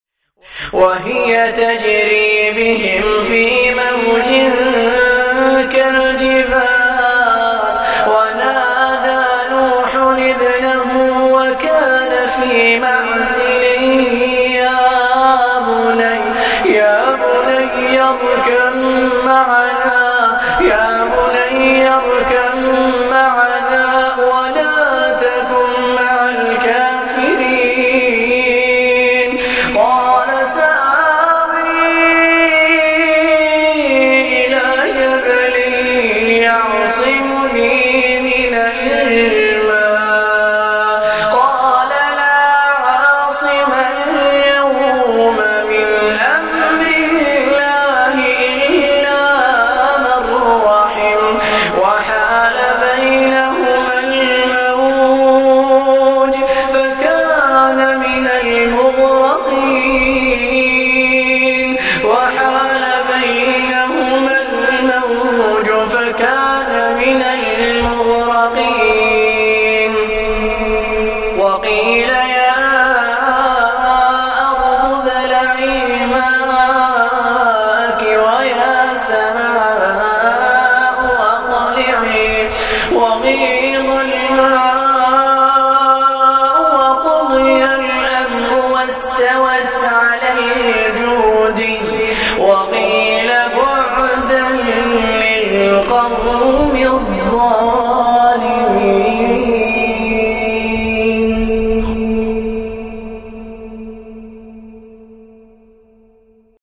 Ansarallah يا من اعطاك الله قدرة السمع حمل هذه التلاوة